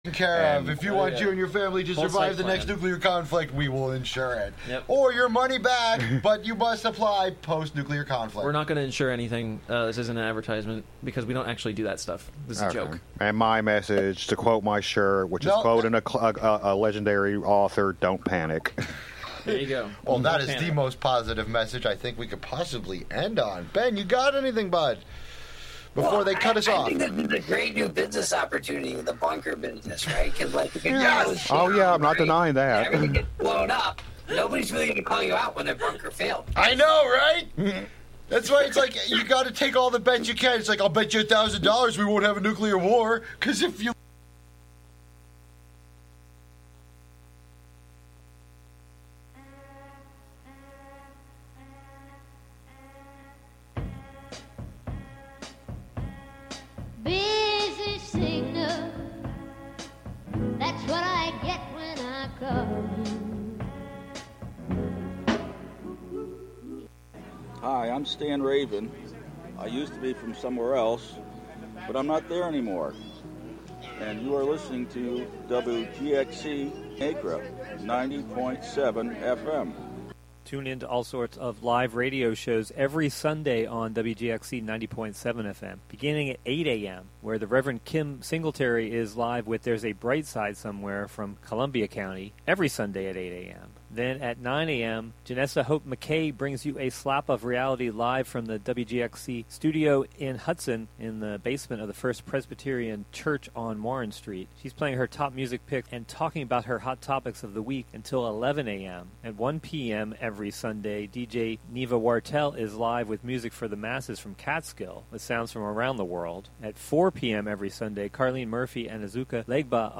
The Love Motel is a monthly late-night radio romance talk show with love songs, relationship advice, and personals for all the lovers in the upper Hudson Valley.